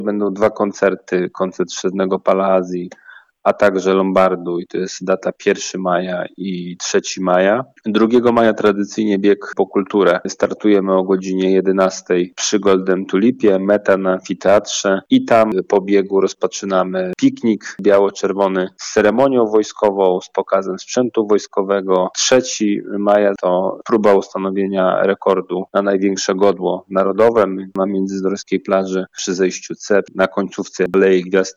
O atrakcjach mówi burmistrz miasta, Mateusz Bobek: „ 1 maja i 3 maja będą koncerty Sztywnego Pala Azji i Lombardu. 2 maja tradycyjnie bieg po kulturę.